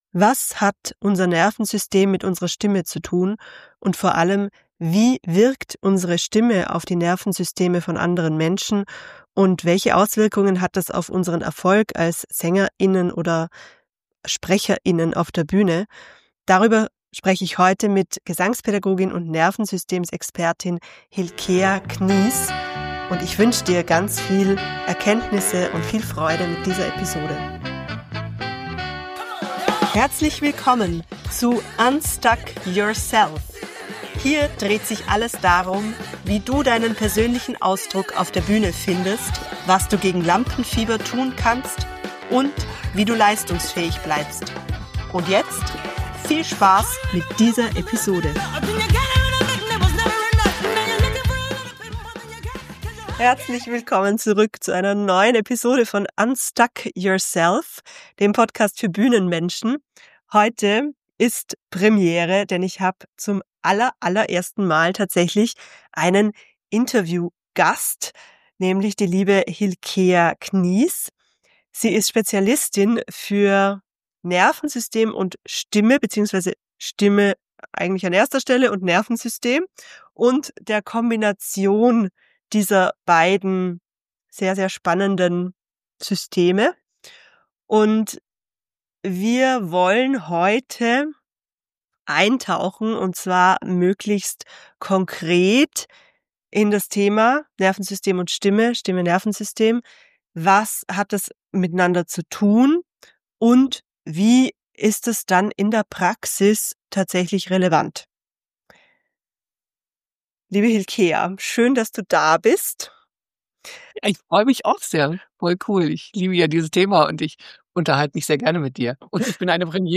Ein - wie ich finde - unglaublich aufschlussreiches und spannendes Gespräch.